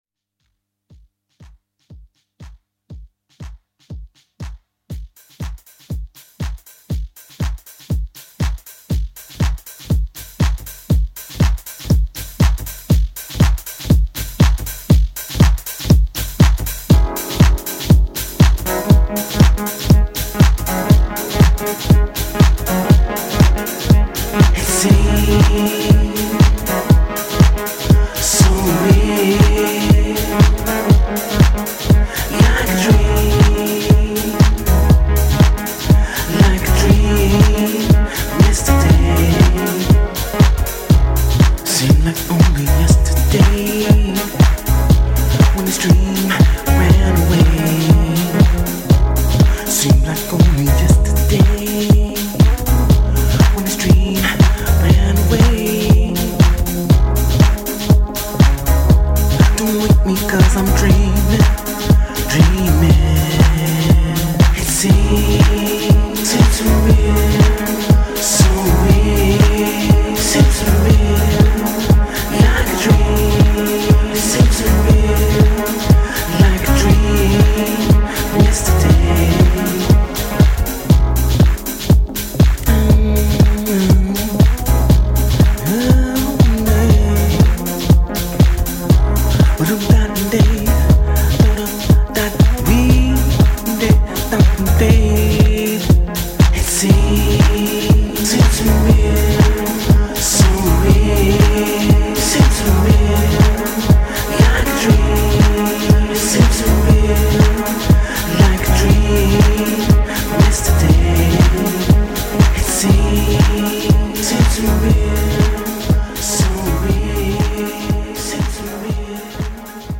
soulful release